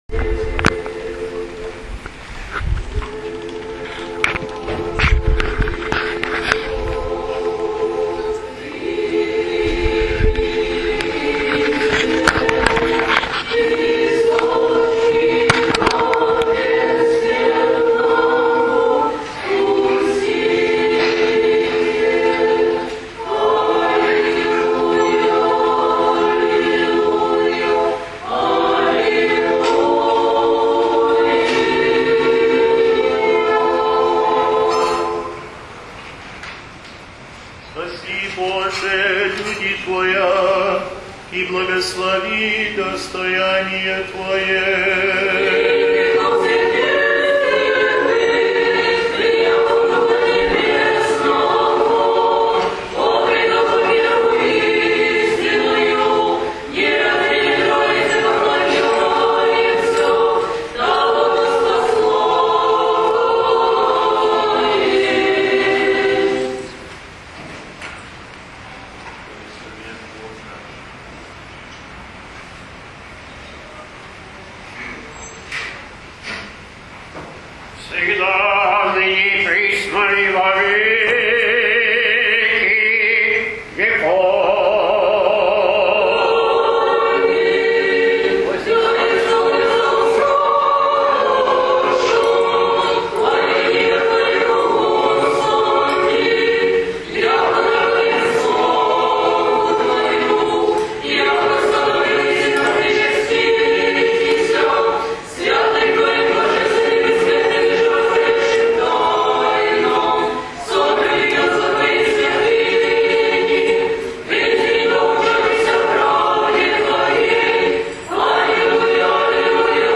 22 Feb 08: Приветственные речи архиереев РПЦ МП и РПЦЗ в день первой совместной службы в Аргентине, 17 февраля 2008г.
В конце службы Митрополит Платон произнес проникновенное приветственное слово и подарил Владыке Илариону панагию и красивый церковный настенный календарь. В свою очередь Владыка Иларион поблагодарил Его Высокопреосвященство, Митрополита Платона и отметил, что наконец сбылось то, о чем зарубежная часть Поместной Русской Церкви десятилетиями молилась Господу.
(Запись начинается с окончанием Св. Причастия, слушайте здесь )